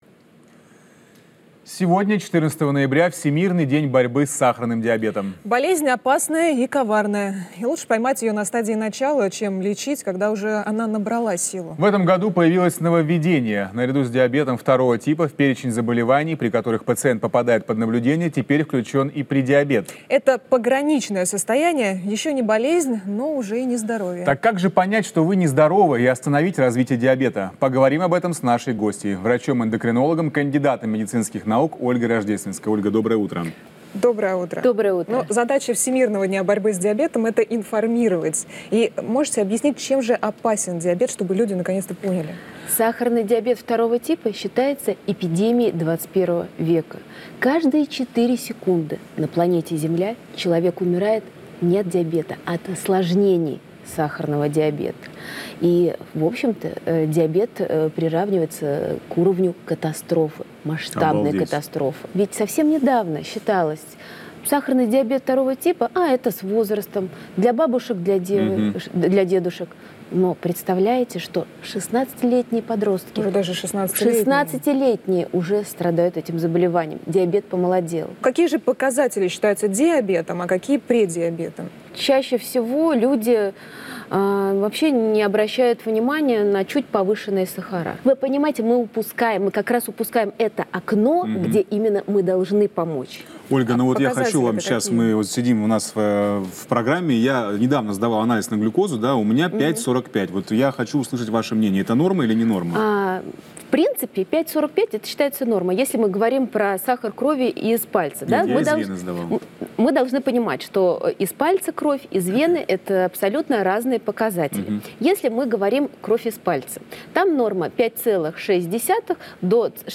Источник: телеканал ТВЦ